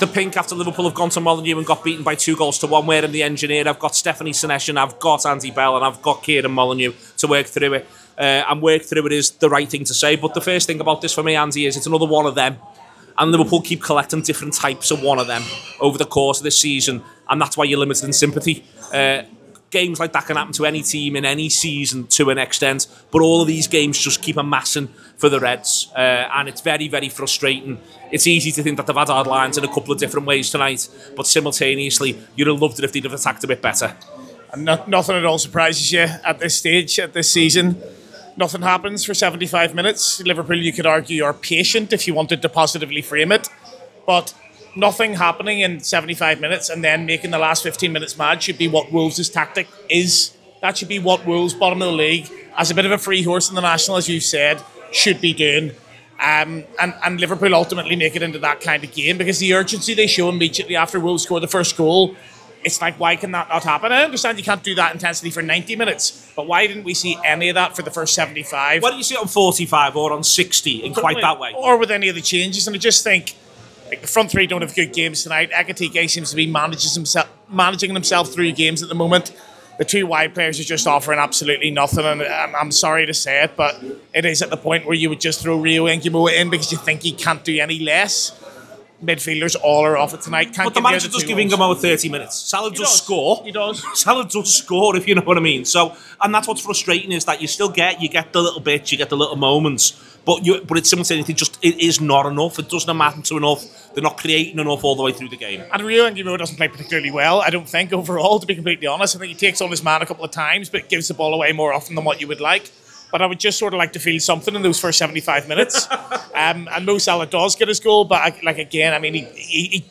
The Anfield Wrap’s post-match reaction podcast following a game in which Liverpool conceded ANOTHER injury time goal consign themselves to another defeat, this time against bottom of the table Wolverhampton Wanderers.